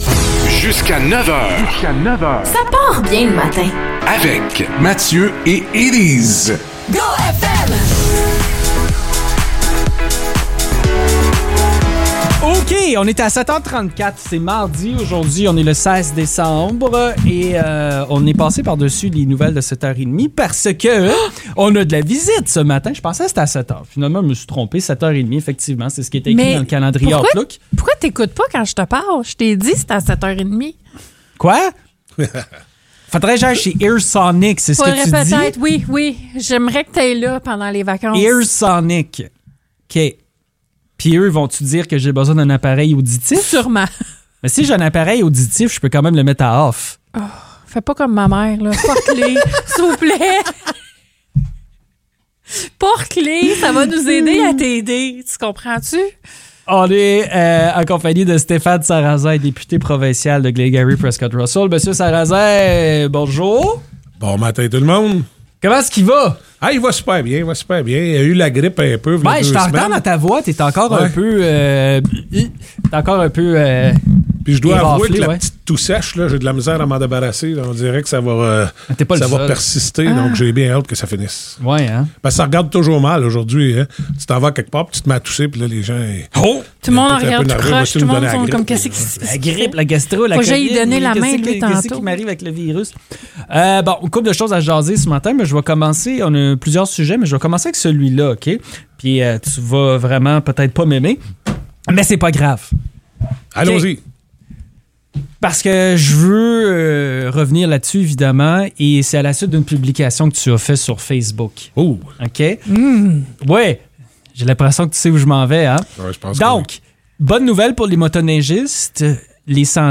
Le député provincial de Glengarry–Prescott–Russell, Stéphane Sarrazin, a passé une heure en studio avec notre équipe.